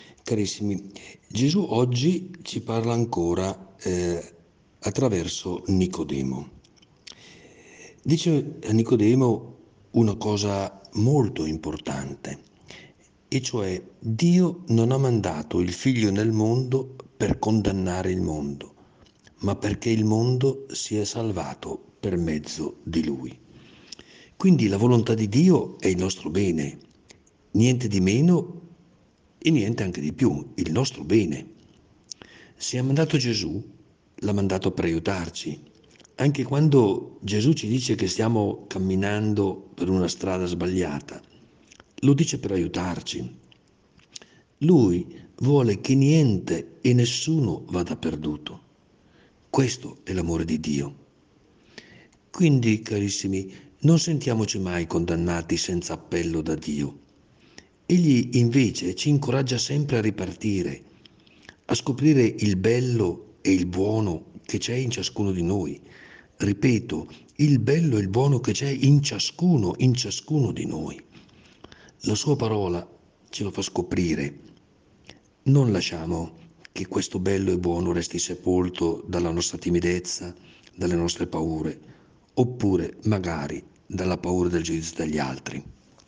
PODCAST – Prosegue la rubrica podcast “In ascolto della Parola”, curata da Mons. Carlo Bresciani, vescovo della Diocesi di San Benedetto del Tronto – Ripatransone – Montalto, il quale ci accompagnerà con un contributo quotidiano.
Il Vescovo commenterà la Parola di Dio per trarne ispirazione per la giornata.